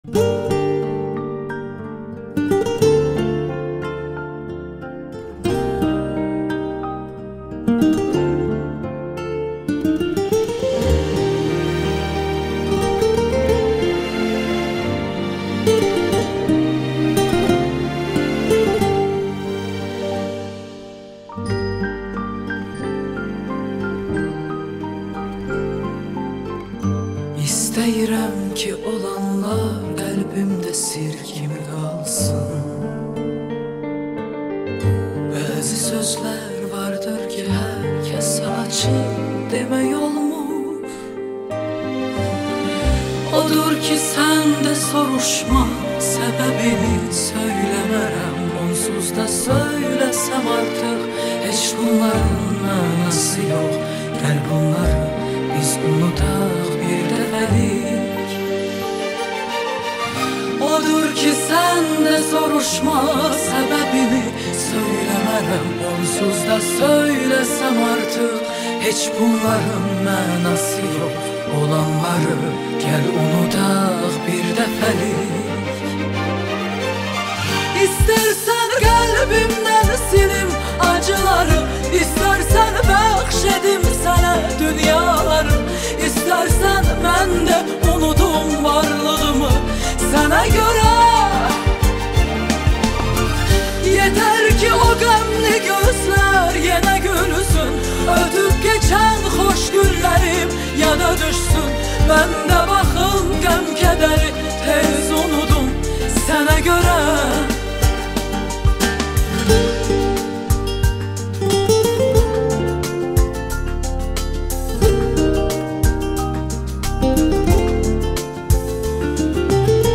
известная своим уникальным стилем и сильным голосом